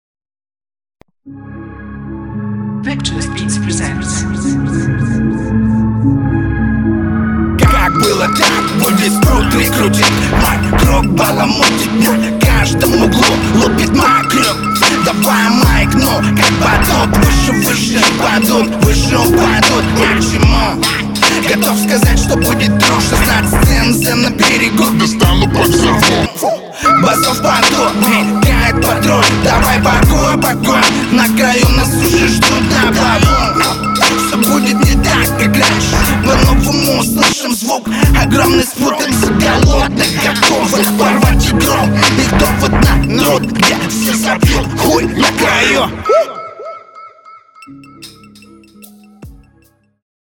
Интересный стиль, но текст тяжело воспринимаем.
Колоритные интонации, но за ними теряется текст, который, если вслушаться идёт пустым наполнением для читки. Насыщай мыслями плотнее